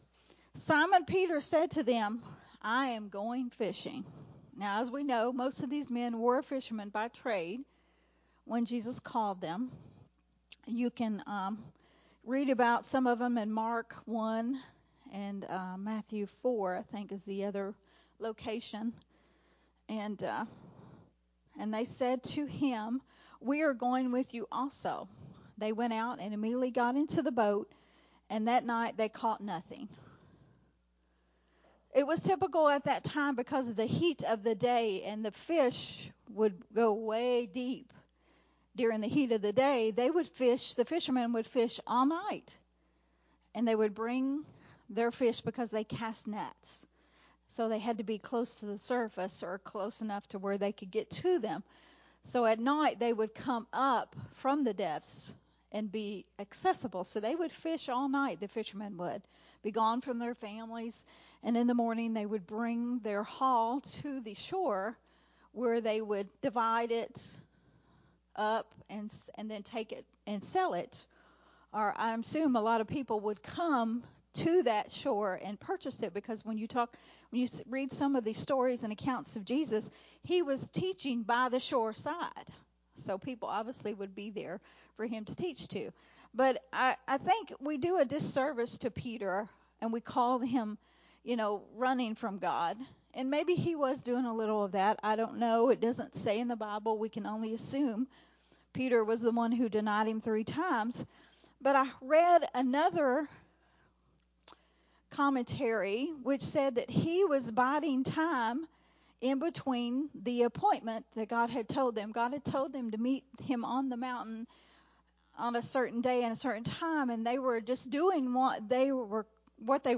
recorded at Unity Worship Center on May 21, 2023.